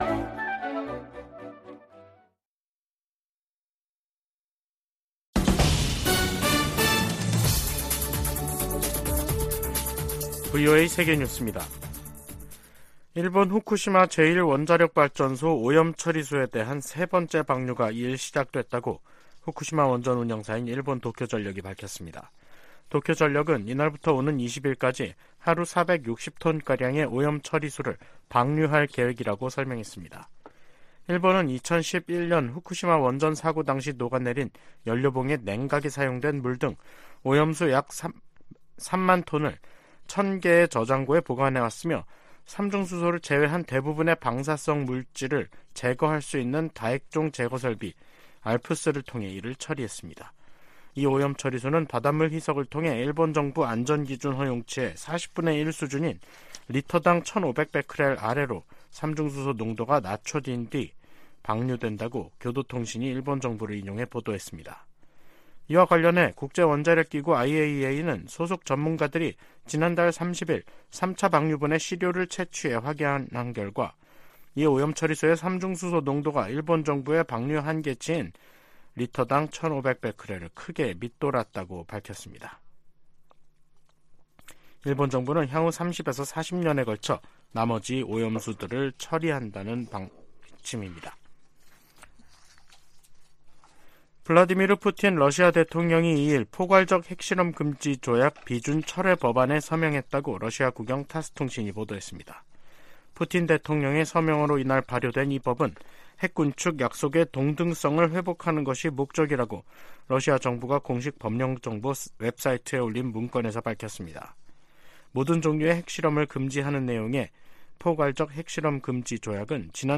VOA 한국어 간판 뉴스 프로그램 '뉴스 투데이', 2023년 11월 2일 3부 방송입니다. 미 국방정보국장이 북한-이란-러시아 연계를 정보 당국의 주시 대상으로 지목했습니다. 조 바이든 미국 대통령이 공석이던 국무부 부장관에 커트 캠벨 백악관 국가안보회의 인도태평양 조정관을 공식 지명했습니다. 북한의 최근 잇따른 대사관 폐쇄는 국제사회의 제재가 작동하고 있다는 증거라고 미국 전직 외교관들이 분석했습니다.